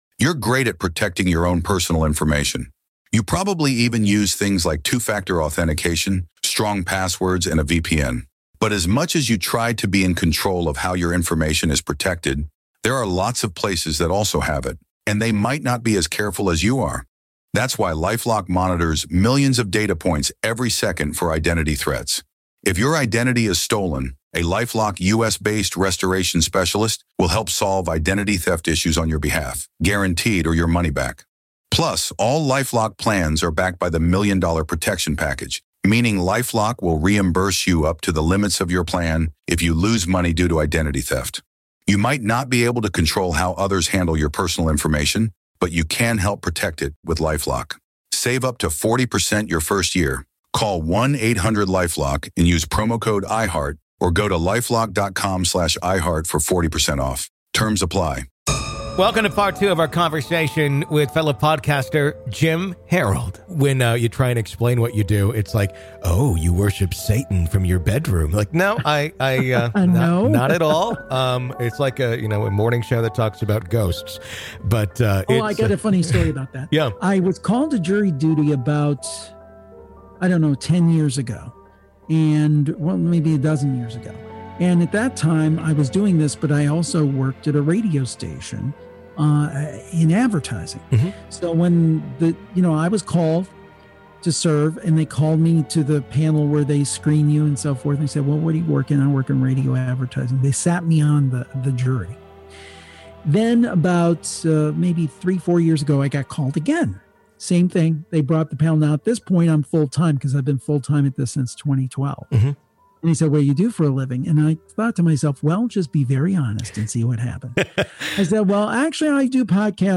Today we discuss life with a fellow broadcaster of the paranormal. This is Part Two of our conversation.